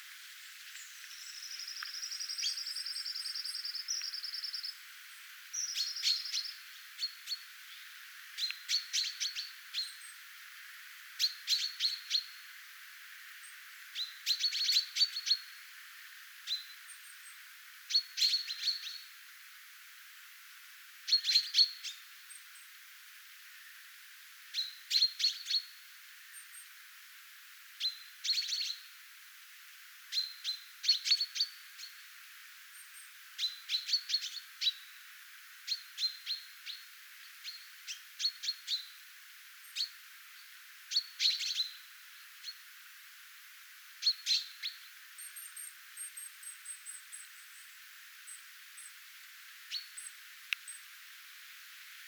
kaksi pyrstötiaisen laulusäettä,
nuoret peipot huomioääntelevät
kaksi_pyrstotiaisen_saetta_nuoret_peipot_huomioaantelevat.mp3